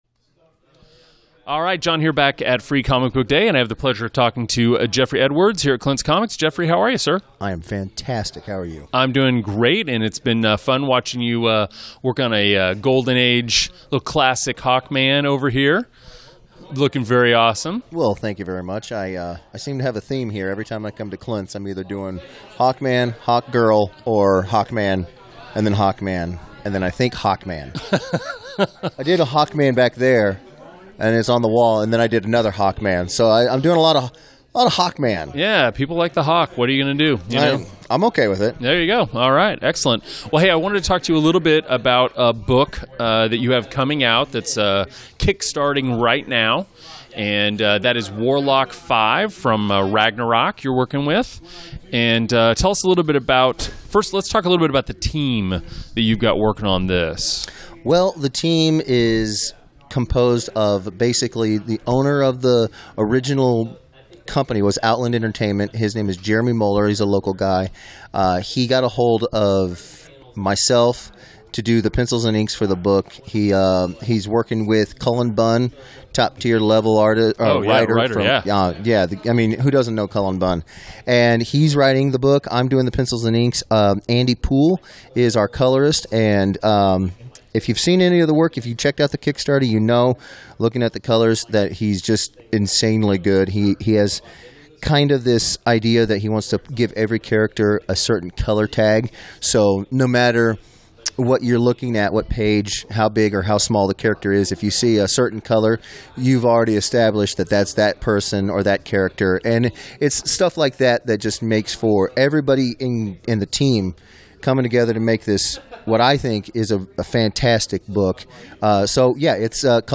WCPE Interview